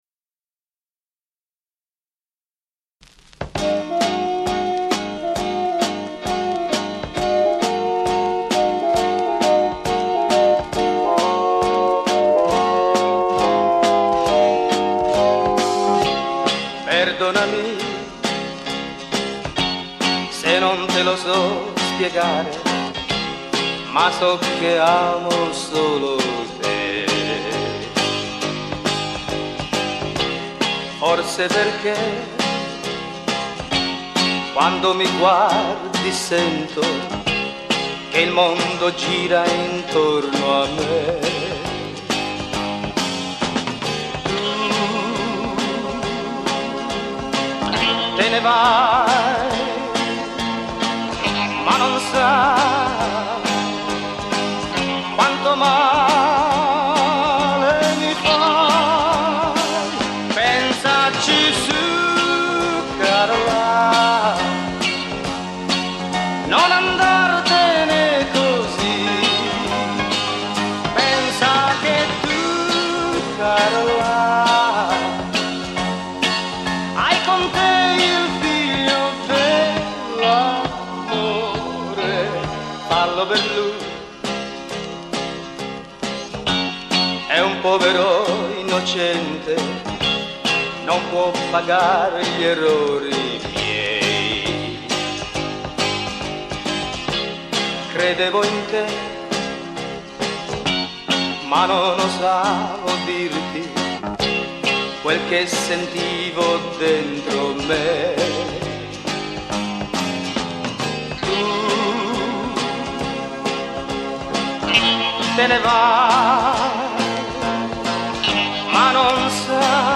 DRUMS
GUITARS
TASTIERE